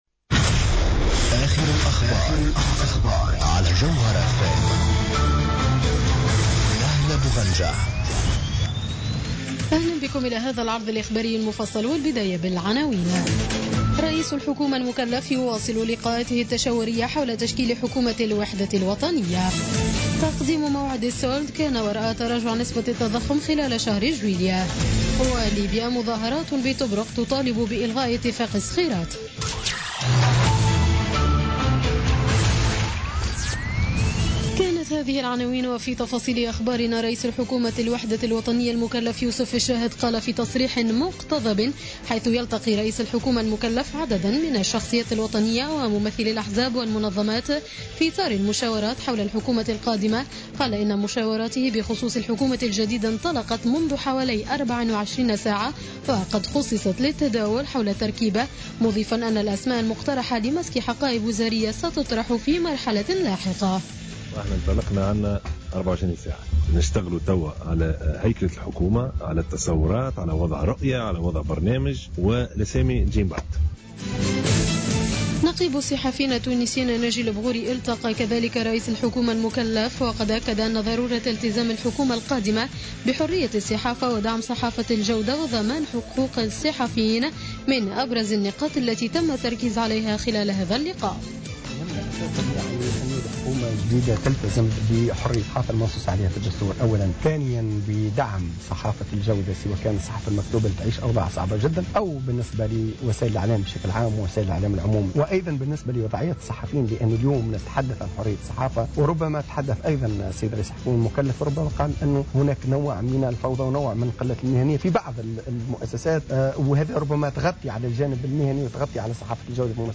نشرة أخبار السابعة مساء ليوم الجمعة 5 أوت 2016